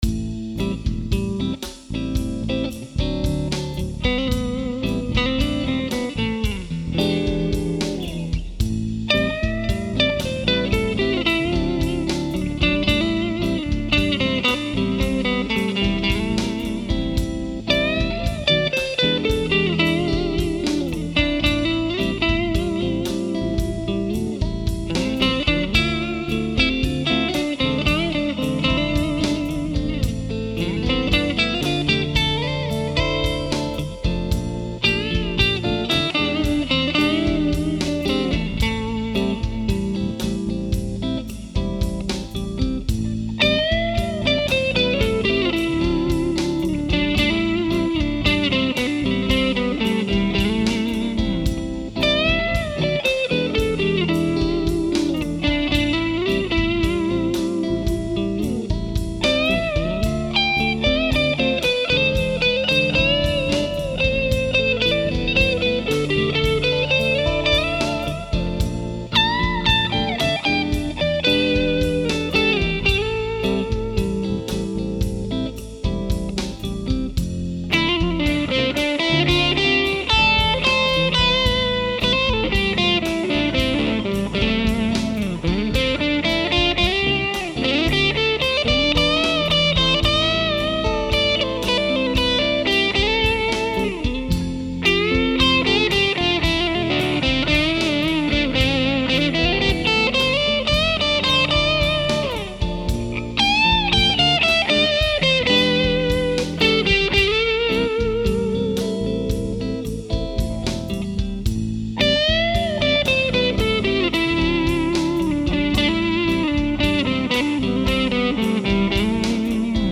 The opening of the song and the first “verse” demonstrate Goldie’s neck pickup in single-coil configuration. Man, it’s chimey like a Strat!
Continuing on, in the bridge of the song, I switch both pickups and remain in channel 2, then I finally finish up back on the neck pickup.